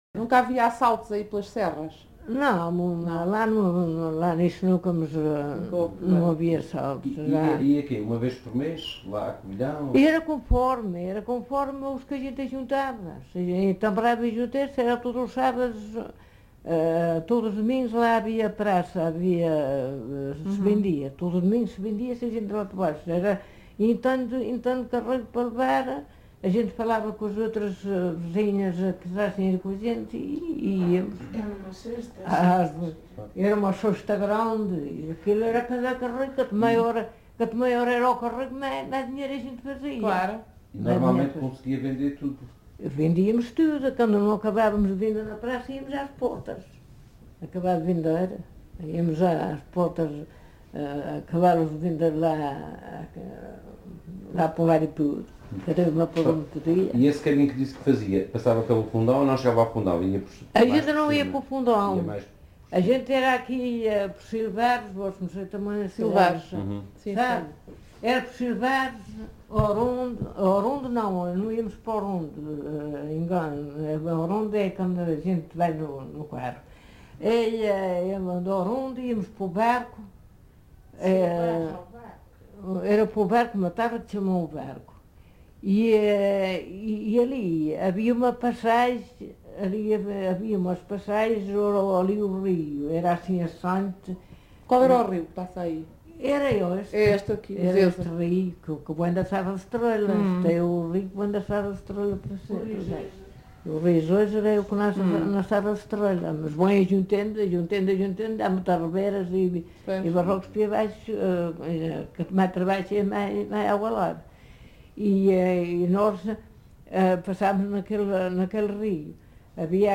LocalidadePorto de Vacas (Pampilhosa da Serra, Coimbra)